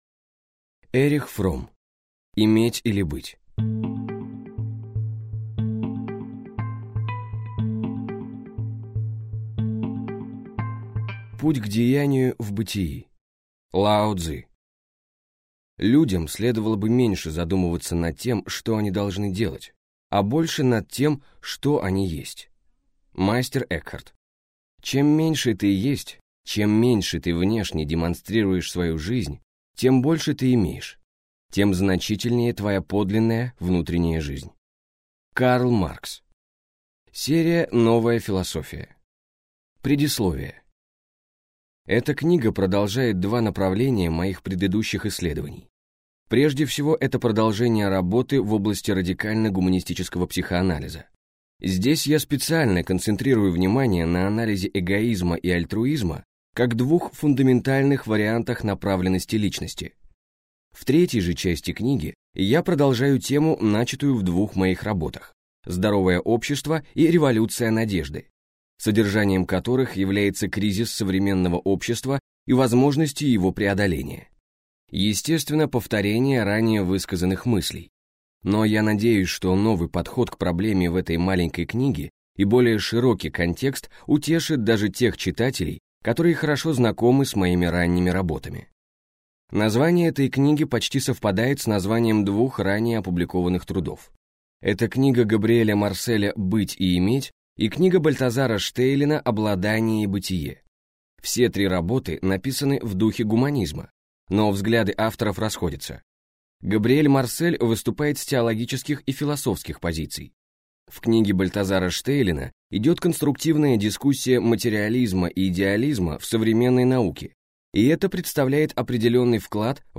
Иметь или быть? - Эрих Фромм - Hörbuch